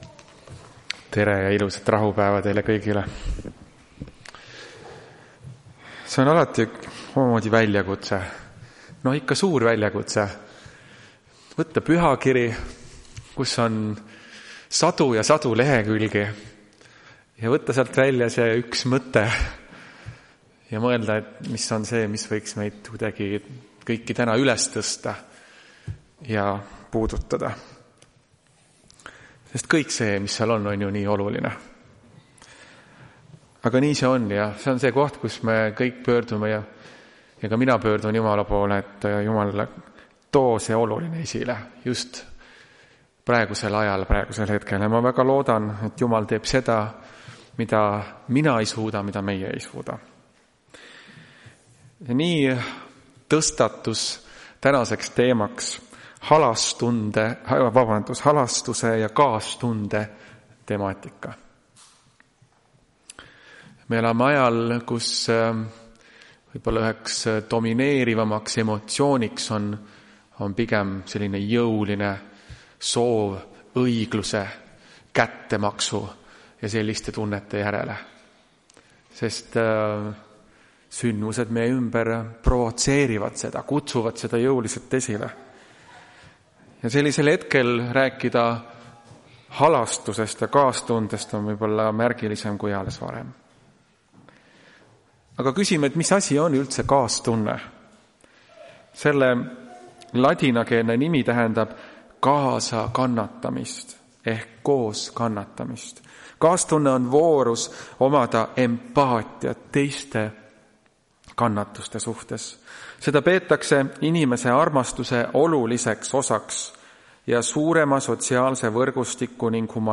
Tartu adventkoguduse 13.09.2025 teenistuse jutluse helisalvestis.